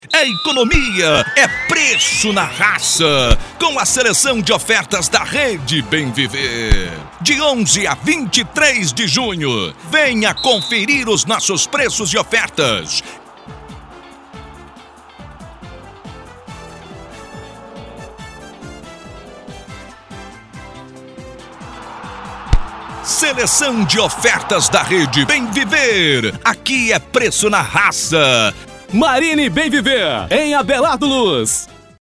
Spot com Janela